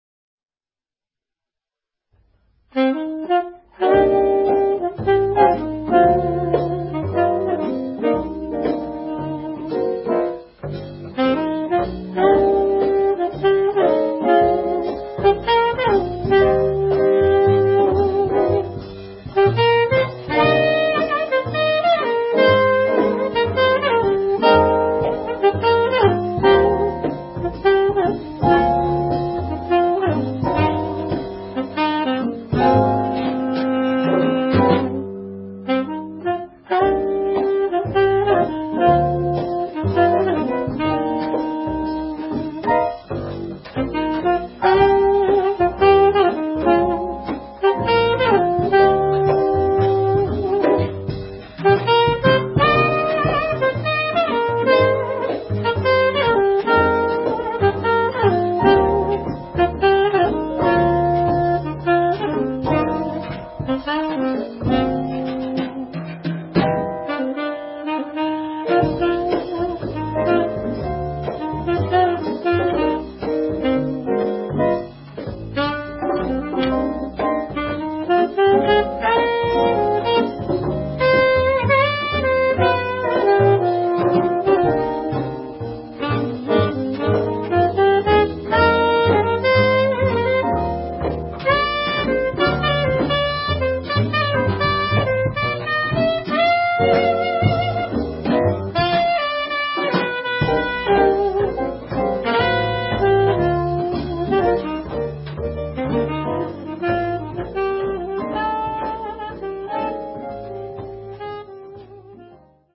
They play a variety of styles, including jazz, and latin grooves inspired by her travels to Cuba and Brazil.